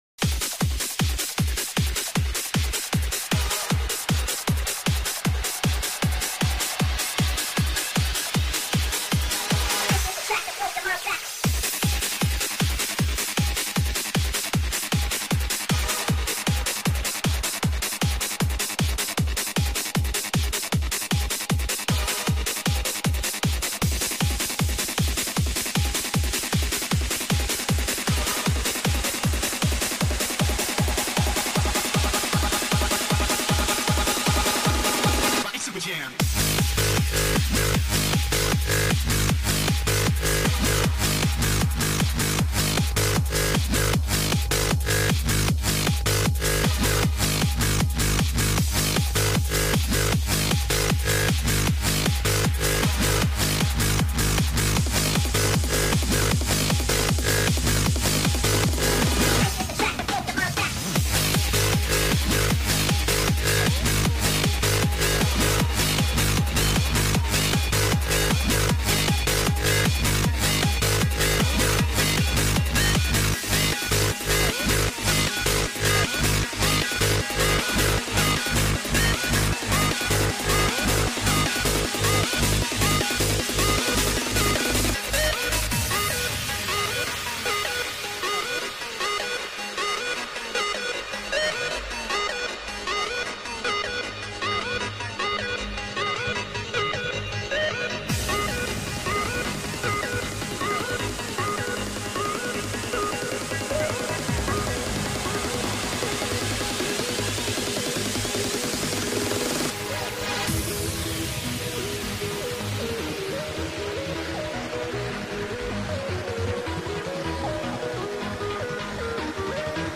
LIVE FROM HID HQ CHUCKING OUT THE VOCALS
Bassline Hardstyle Vocals